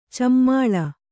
♪ cammāḷa